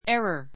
érə r エ ラ